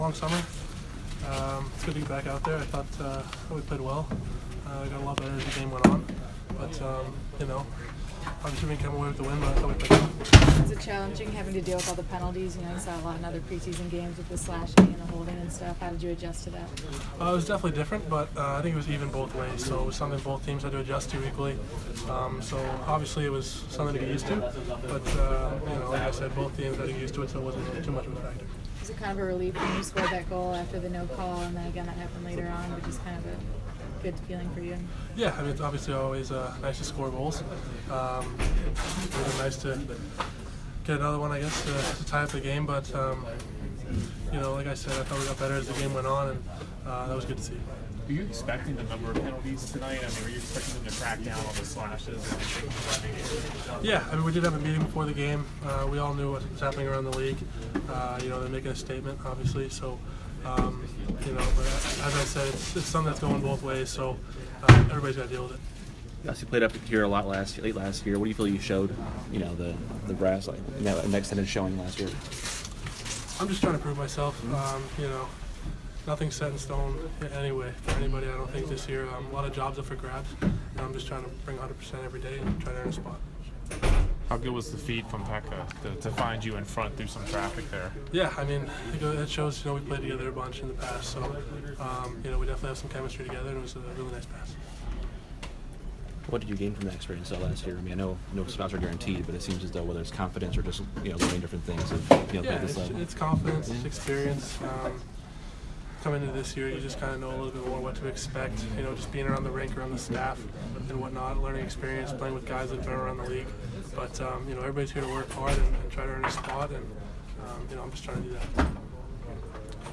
Adam Erne Post game quotes 9/19